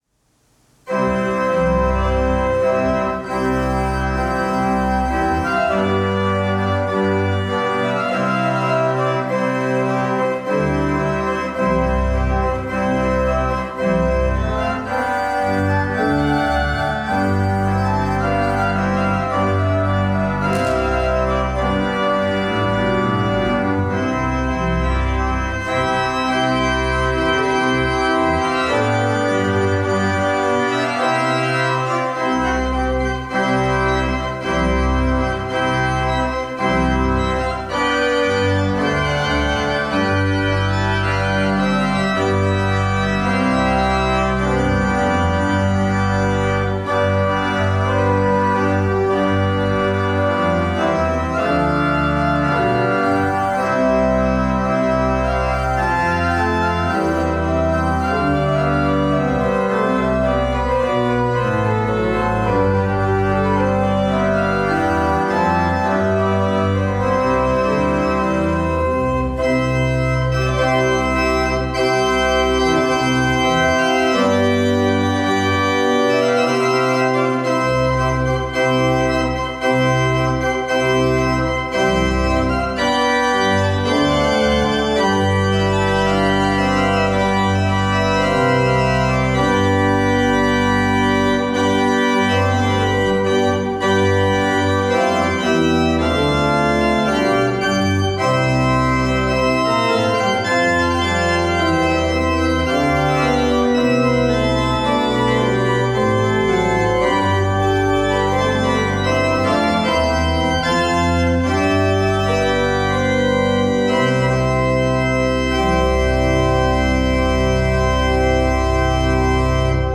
Service of Worship
Postlude
March